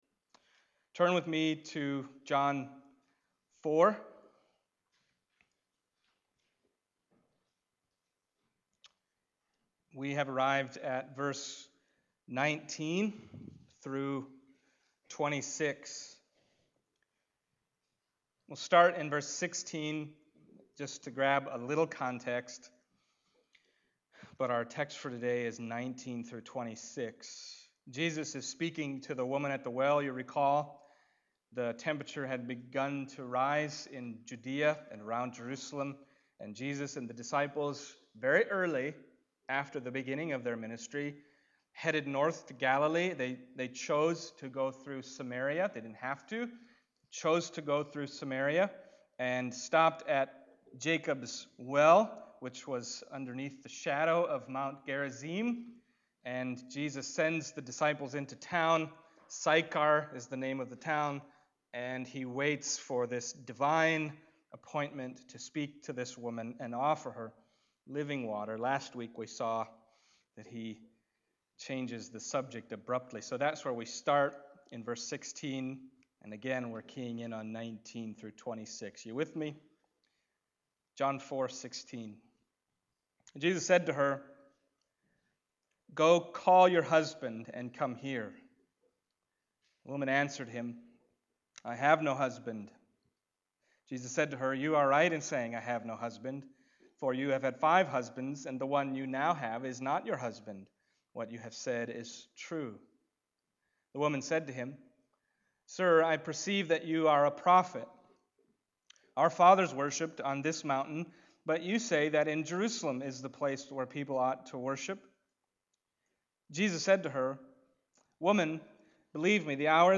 John Passage: John 4:19-26 Service Type: Sunday Morning John 4:19-26 « Come